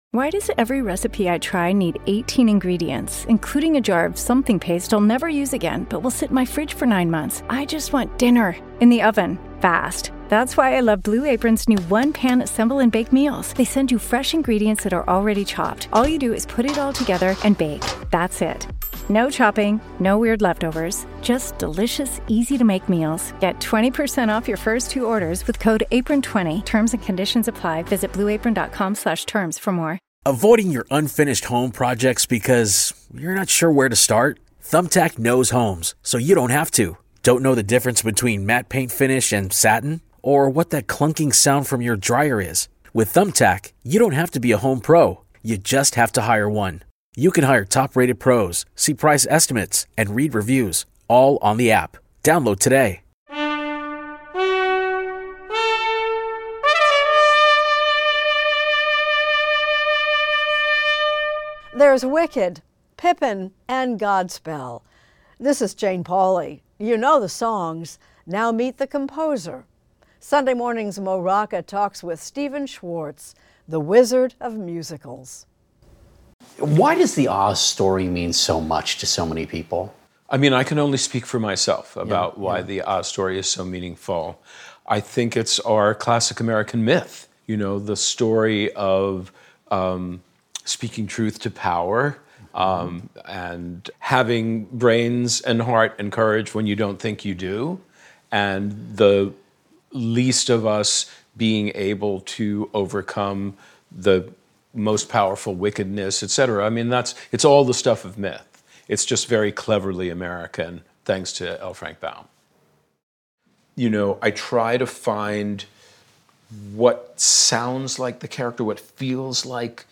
Extended Interview: Stephen Schwartz